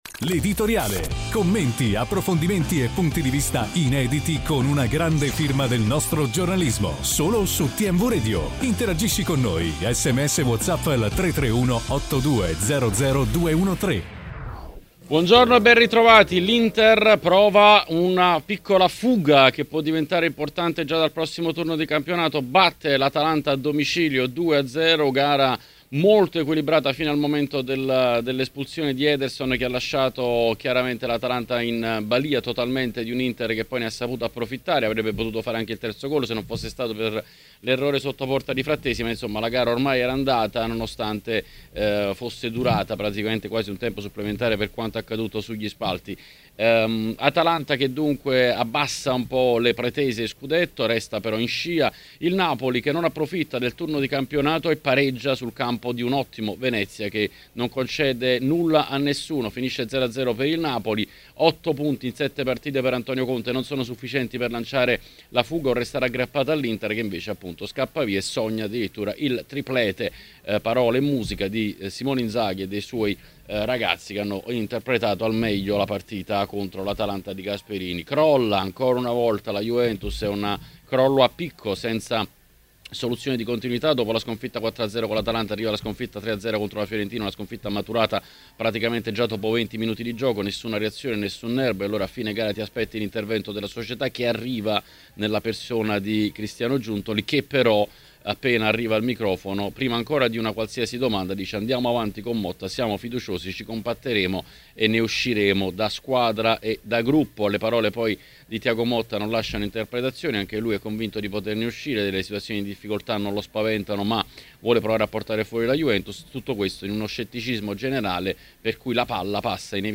Ospite de L'Editoriale di TMW Radio